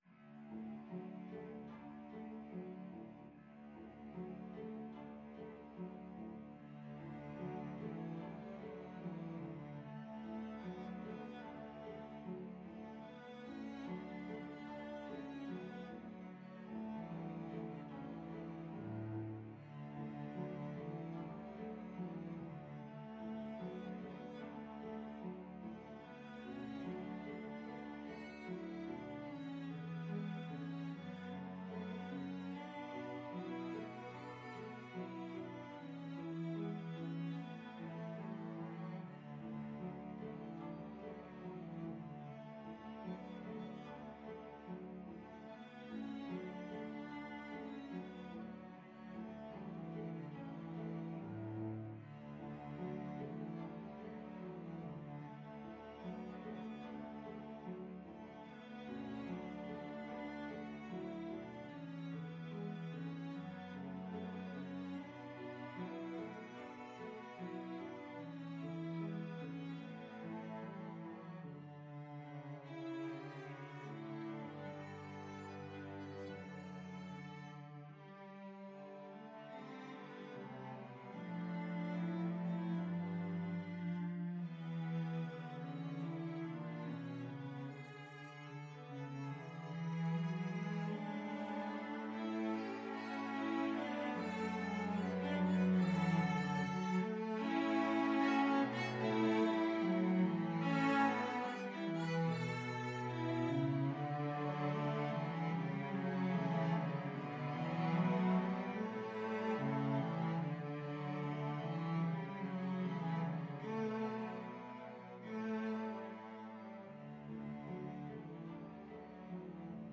Free Sheet music for Cello Trio
Andantino quasi allegretto ( = 74) (View more music marked Andantino)
D major (Sounding Pitch) (View more D major Music for Cello Trio )
4/4 (View more 4/4 Music)
Classical (View more Classical Cello Trio Music)